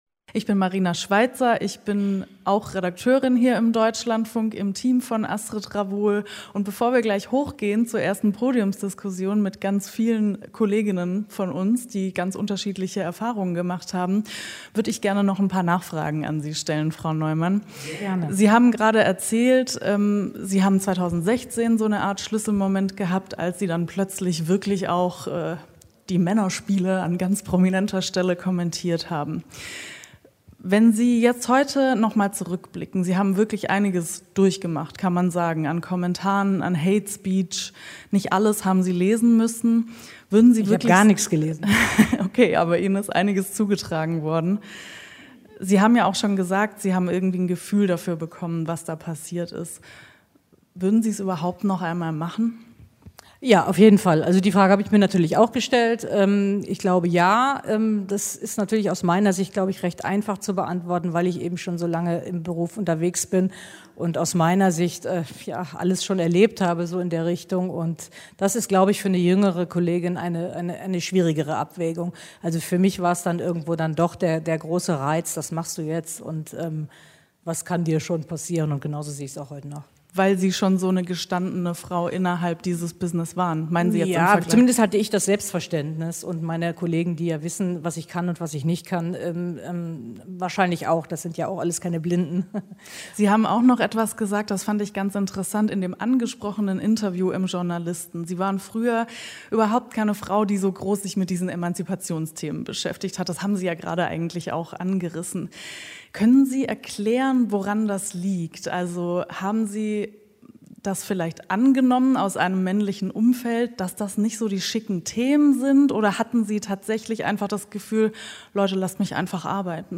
Sportkonferenz - 2021: Erste Podiumsdiskussion